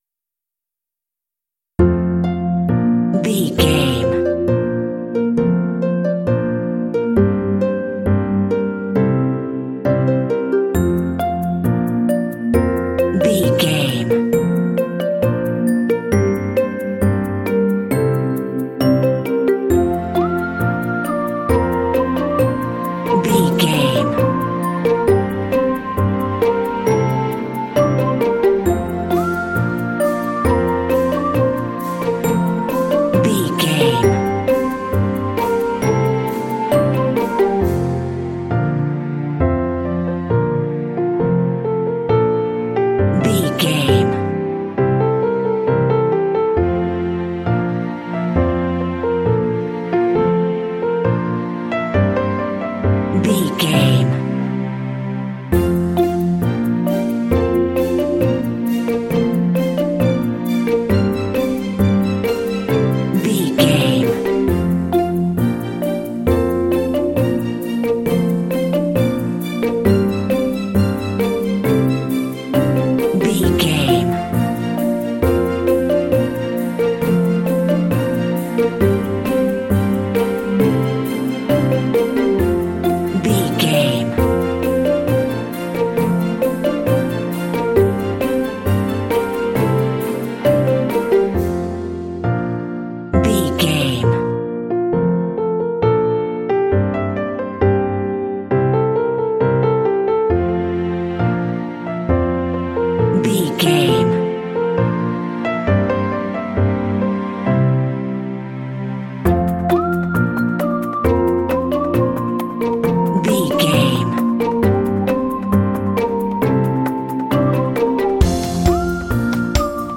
Uplifting
Ionian/Major
bright
optimistic
piano
harp
percussion
strings
drums
contemporary underscore